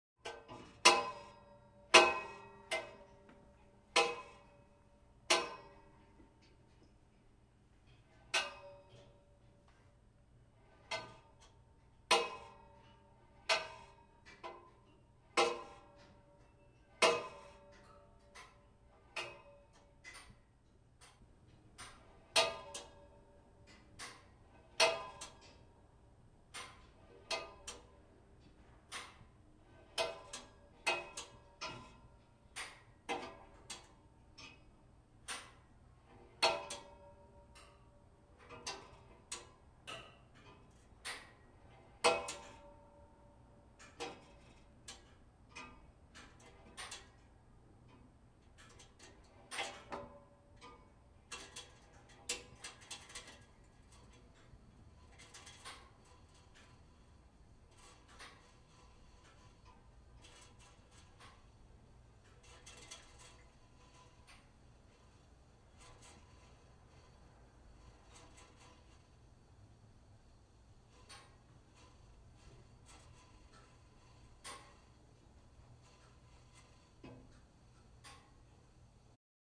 На этой странице собраны звуки работающего радиатора — от мягкого потрескивания до монотонного гула.
Звук стука и нагревания отопительной батареи